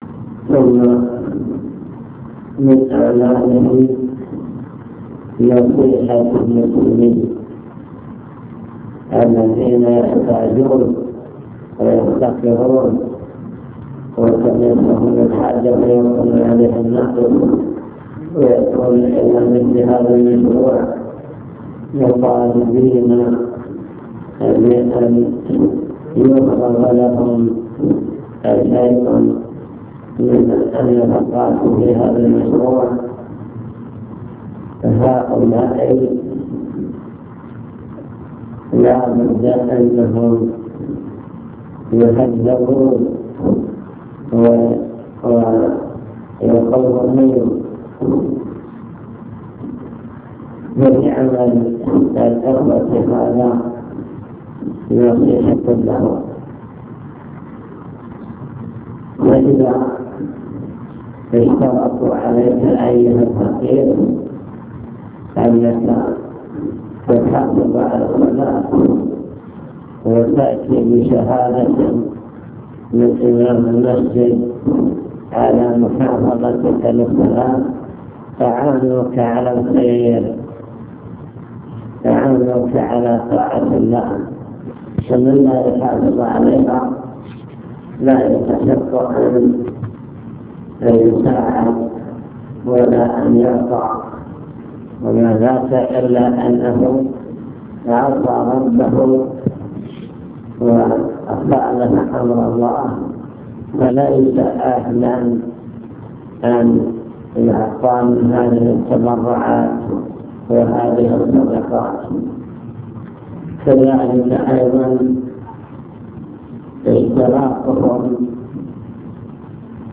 المكتبة الصوتية  تسجيلات - لقاءات  كلمة مجلس مستودع الأوقاف